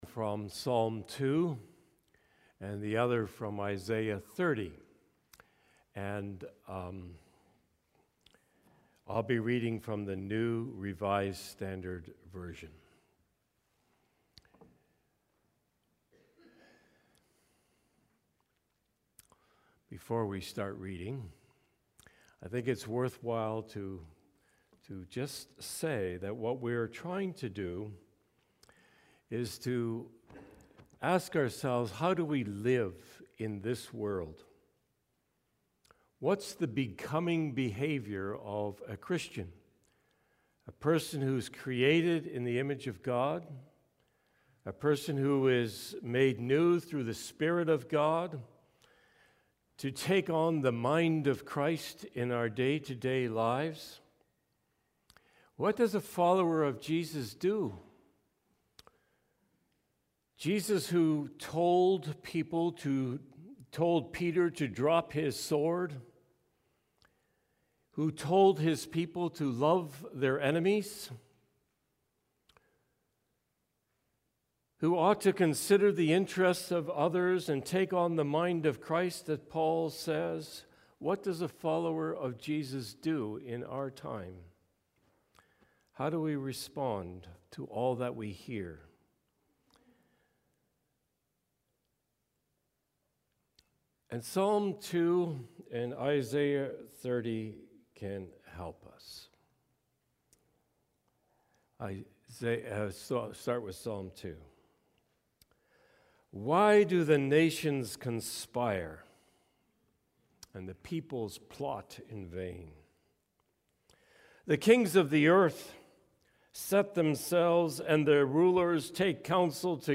Sermons | Ebenezer Christian Reformed Church
Guest Speaker